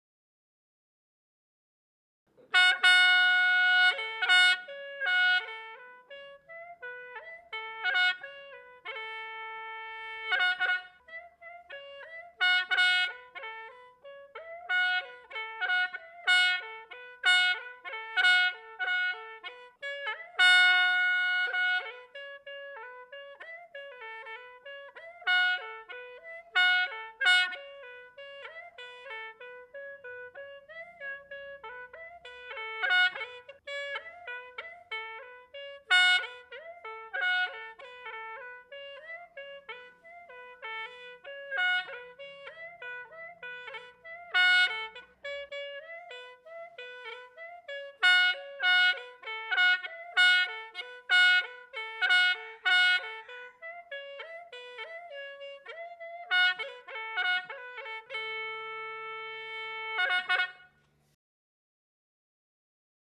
reed pipe solo the instrument has finger holes like a side flute but uses a brass reed to make the sound 900KB
Track 40 Hmong reed pipe.mp3